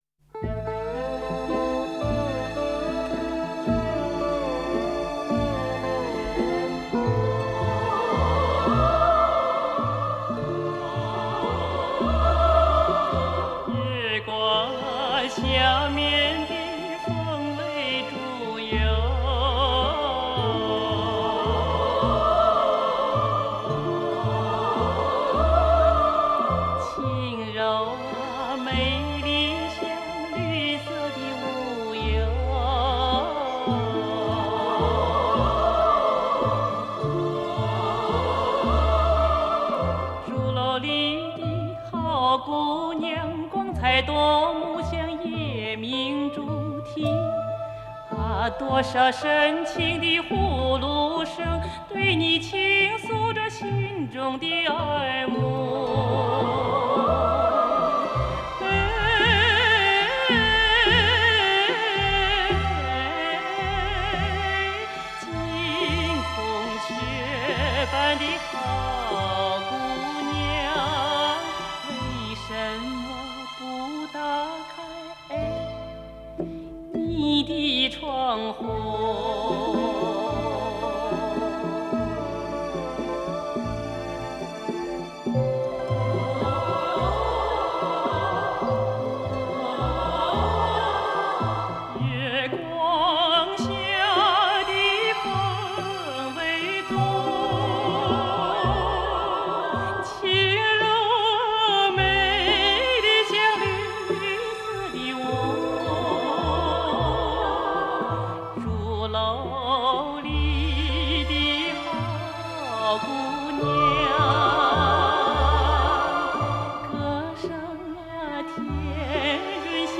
流行音乐